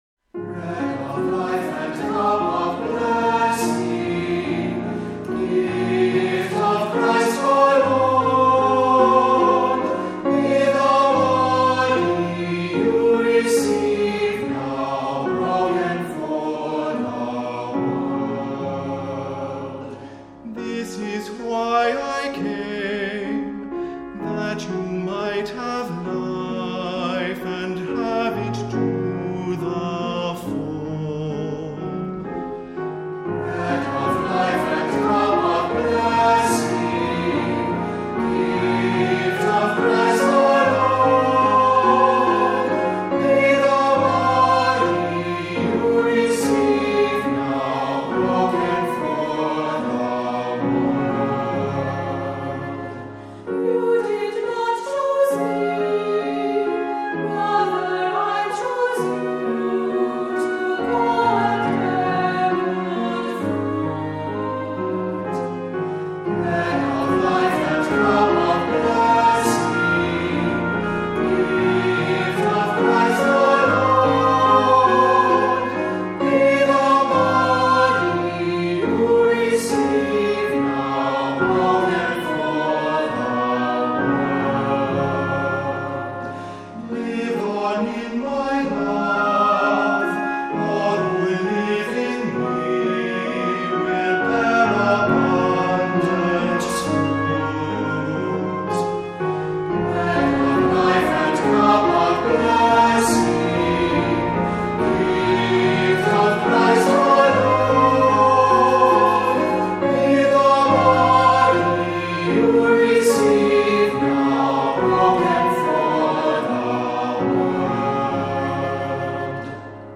Voicing: Cantor, assembly,Unison Choir